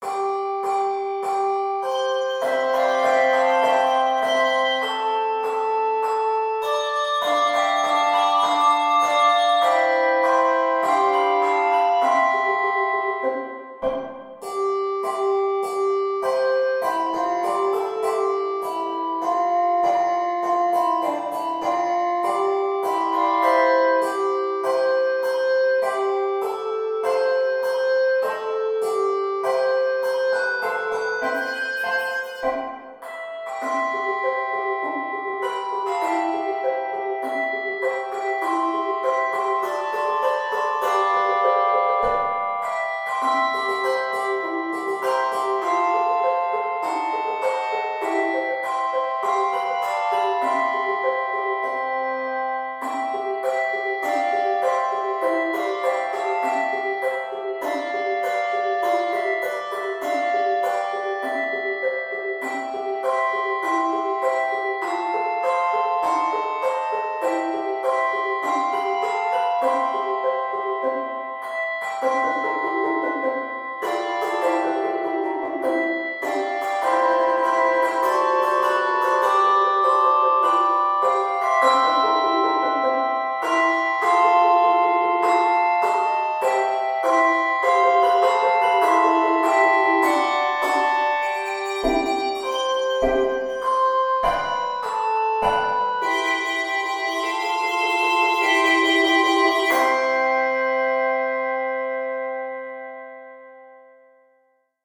hymn favorite
arranged for small handbell ensemble
Key of C Major. 43 measures.